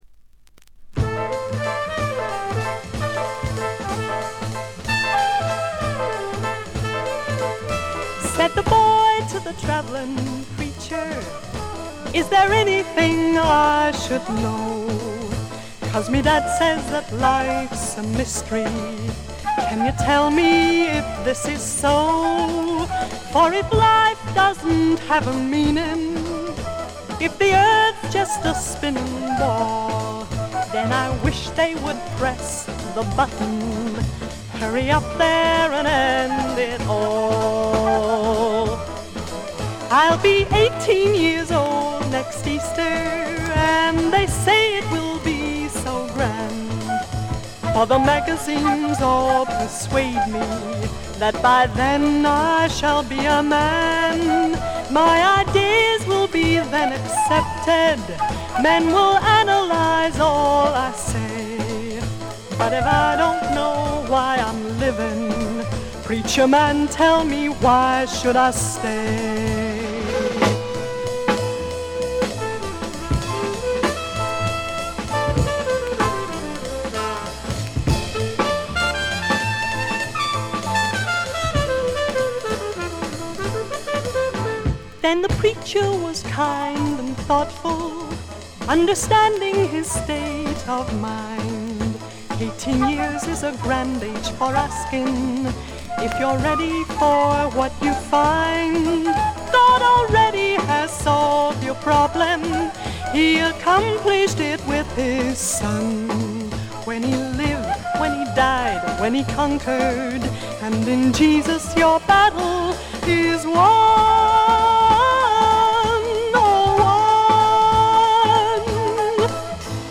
プレスが良くないのか見た目より悪くて、全体にバックグラウンドノイズ、チリプチ多め大きめ、散発的なプツ音少々。
存在感のあるアルトヴォイスがとてもいい味をかもし出して、個人的にも大の愛聴盤であります。
試聴曲は現品からの取り込み音源です。